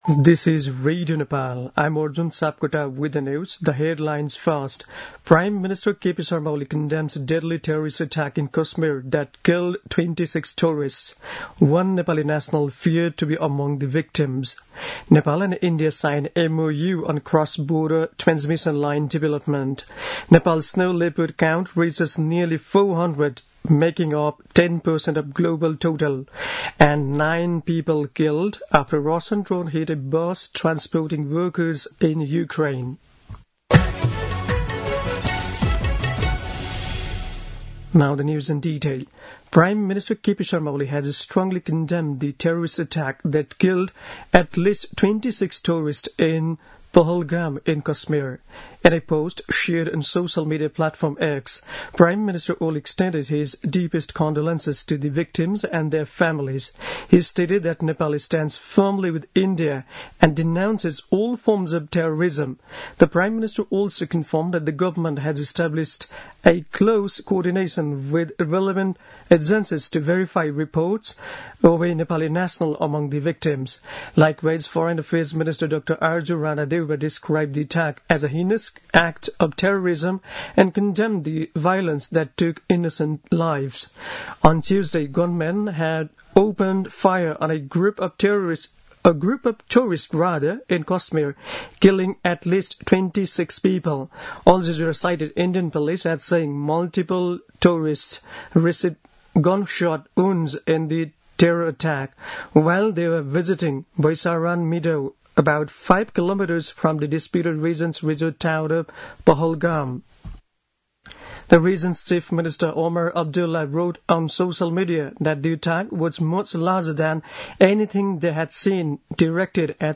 दिउँसो २ बजेको अङ्ग्रेजी समाचार : १० वैशाख , २०८२